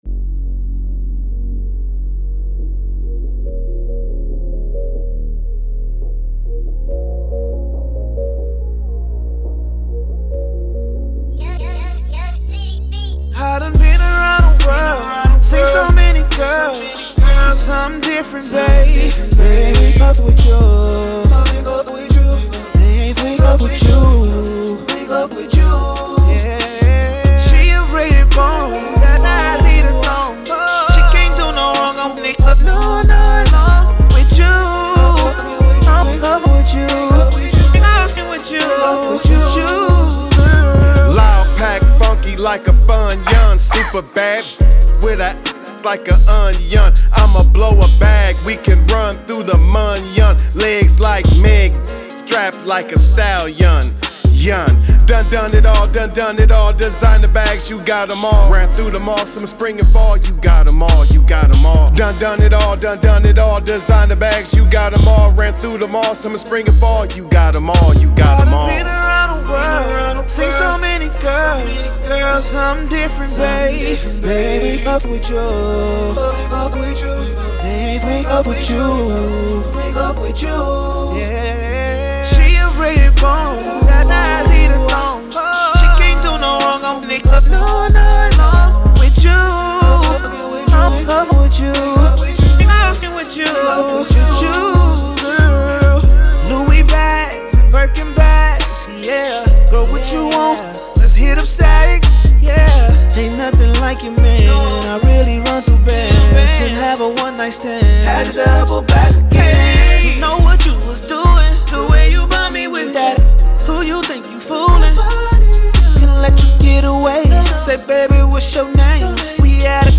Hiphop
rap/R&B fusion